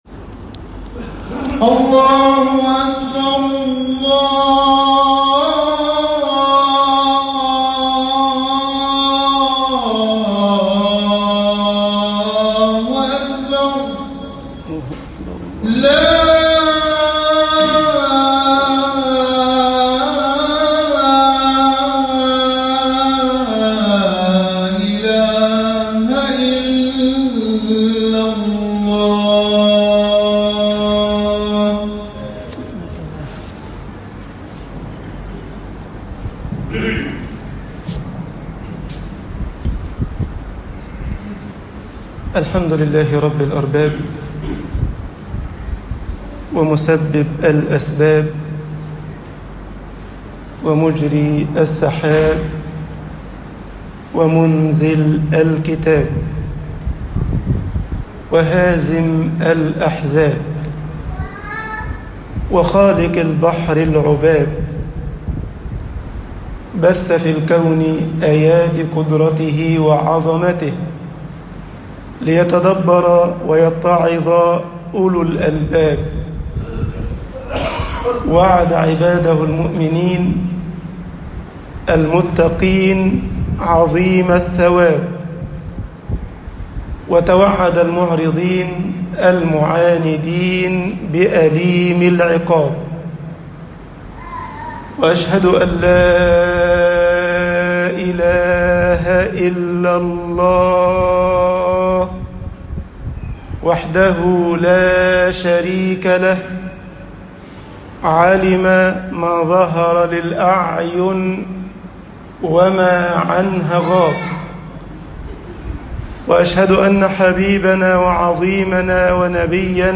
خطب الجمعة - مصر عبادة الهوى مهلكة طباعة البريد الإلكتروني التفاصيل كتب بواسطة